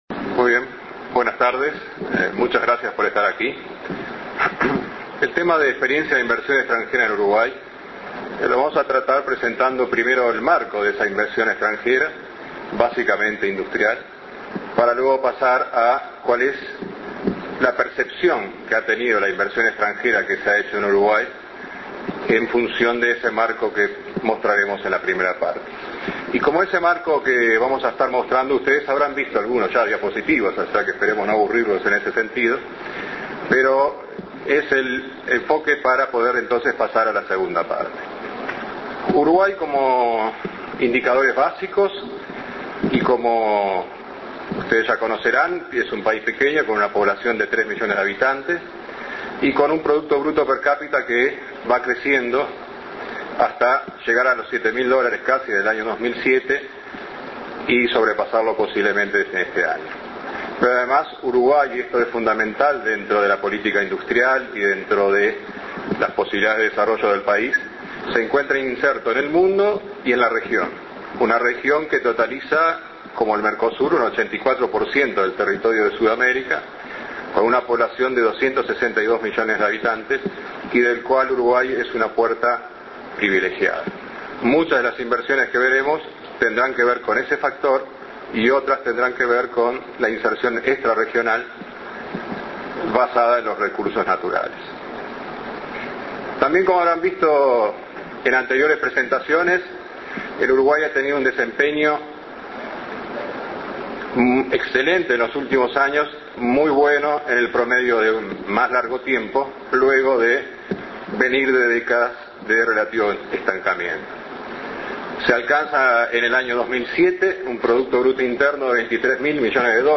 RONDA URUGUAY 2009
Palabras de Roberto Kreimerman Escuchar MP3